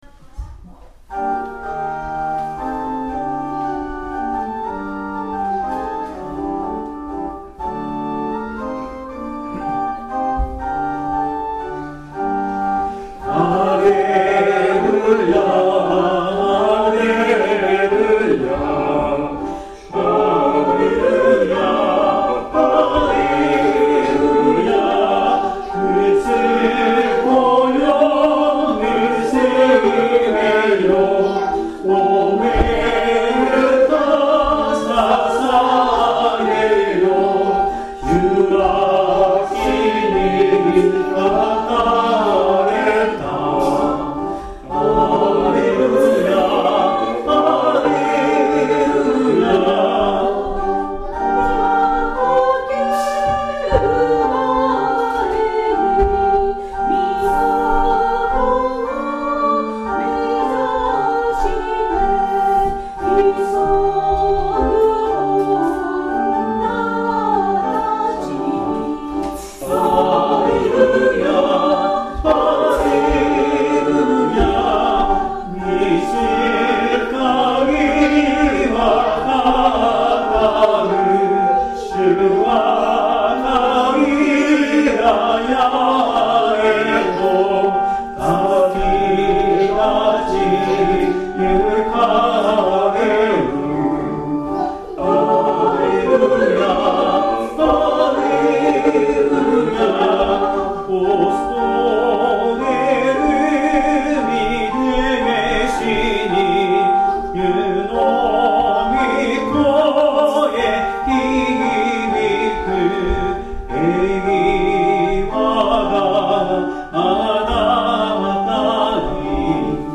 ♪聖歌隊練習補助
Tonality = g　Pitch = 440
Temperament = Equal
◆練習での録音
♪ Pre 　1. Unison　 2.女声 　3.男声　4.T.ソロ 　5.Unison
+Reverve:S-Room